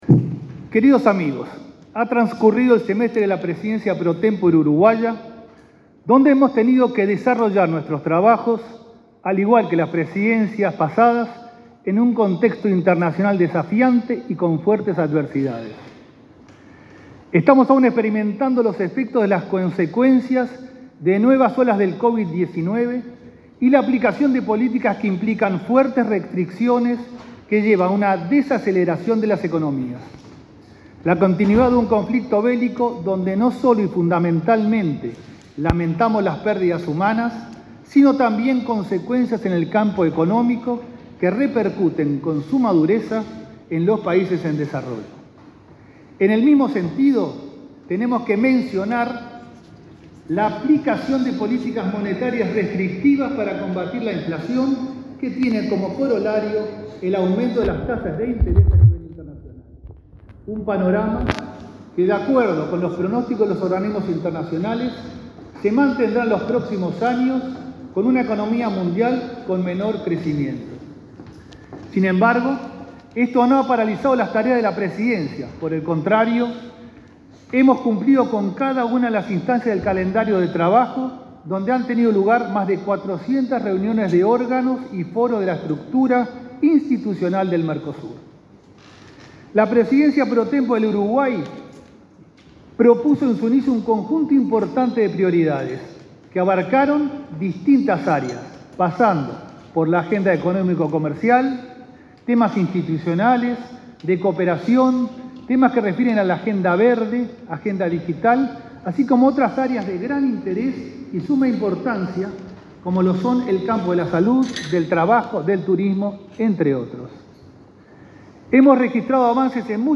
Disertación del canciller uruguayo, Francisco Bustillo
El canciller uruguayo, Francisco Bustillo, presidió, este lunes 5 en Montevideo, la LXI Reunión Ordinaria del Consejo del Mercado Común.